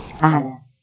speech
keyword-spotting
speech-commands